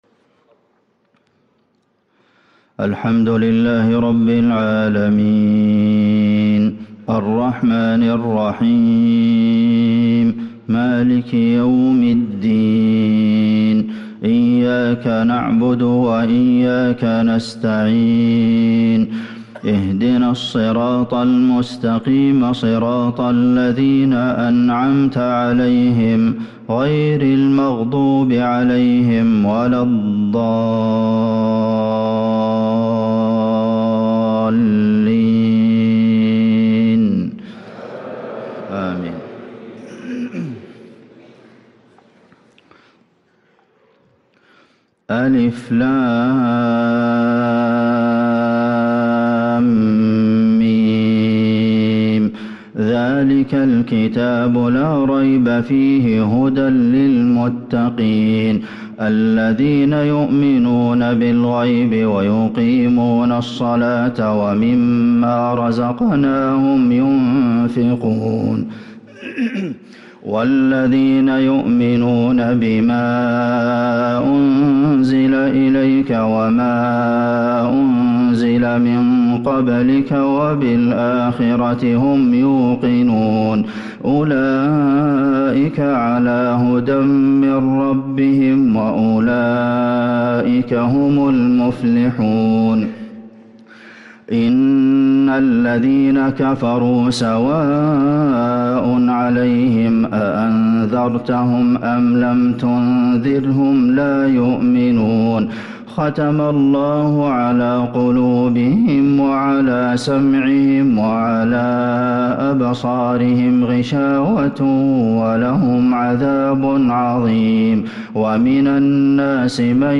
صلاة الفجر للقارئ عبدالمحسن القاسم 21 جمادي الأول 1445 هـ
تِلَاوَات الْحَرَمَيْن .